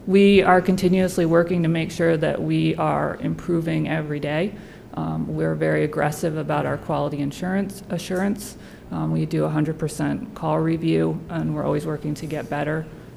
In a presentation to City Council